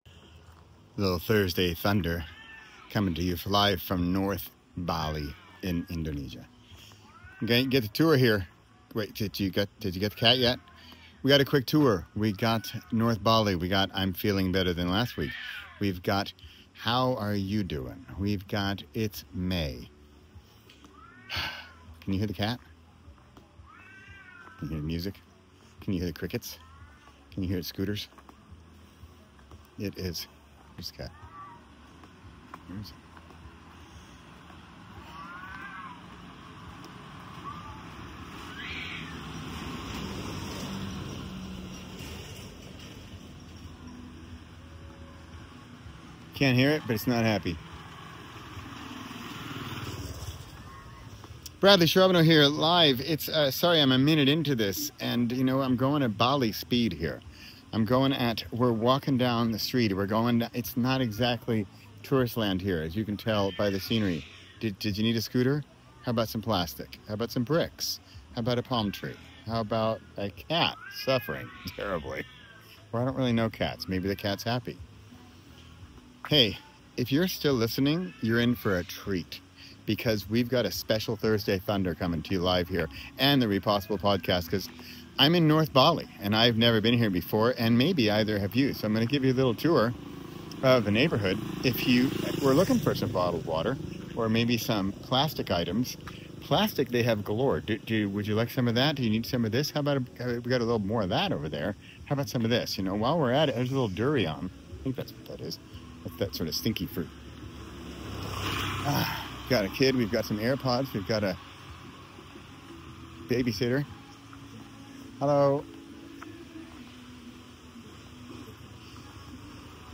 re439: Lost in North Bali (and Loving It) | A Jungle-Wonky Thursday Thunder Walkabout